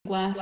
A segment of the "Spoken Text" audio file, specifically the word 'glass'. No further audio effects were added.